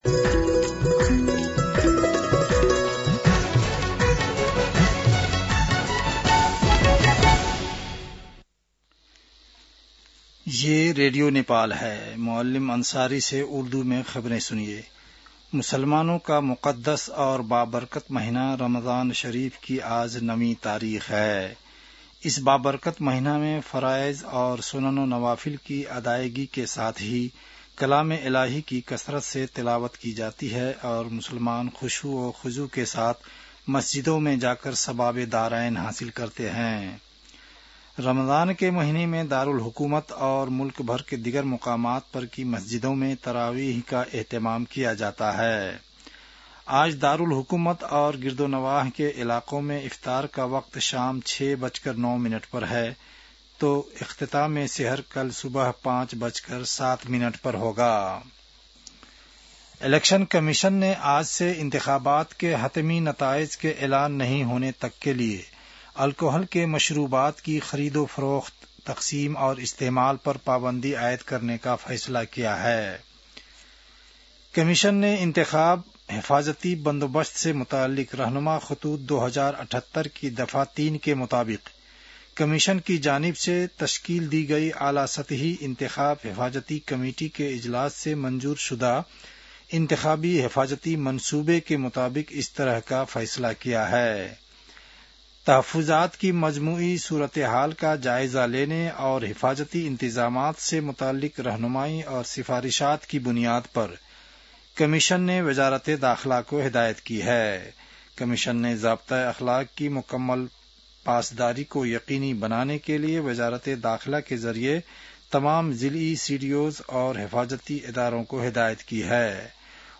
उर्दु भाषामा समाचार : १५ फागुन , २०८२